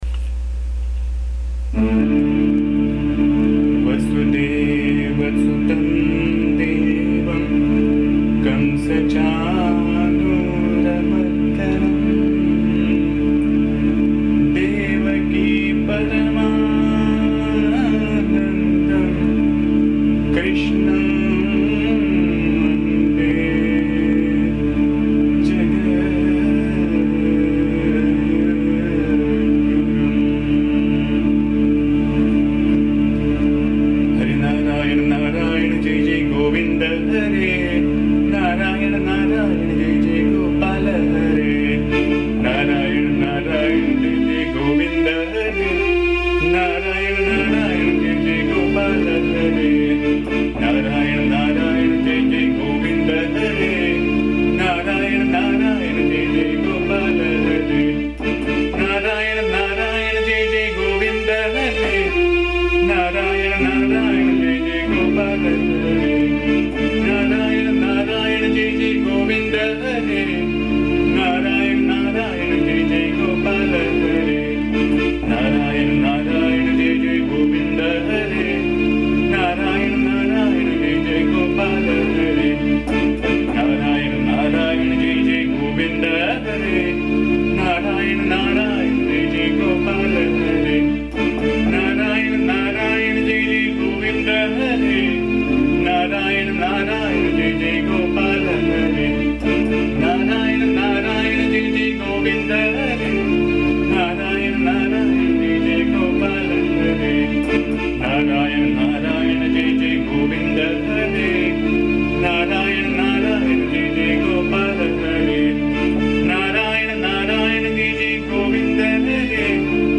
Please bear the noise, disturbance and awful singing as am not a singer.
AMMA's bhajan song